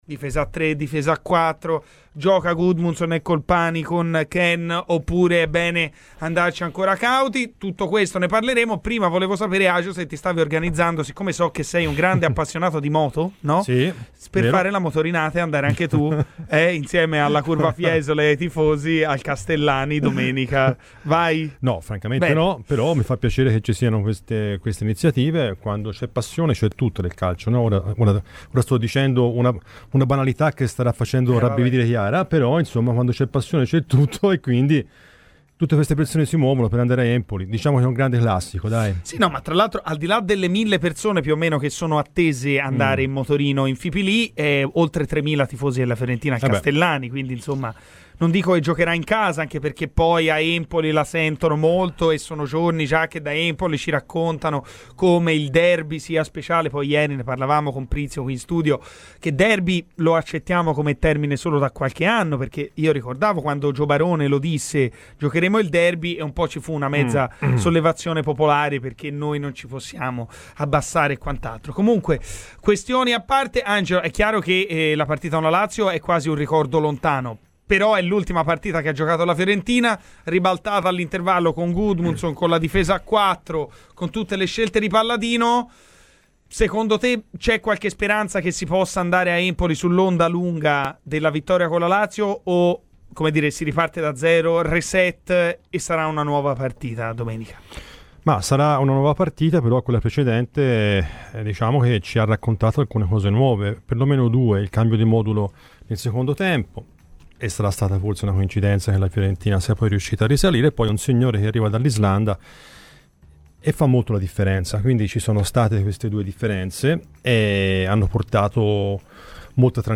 Come ogni venerdì in studio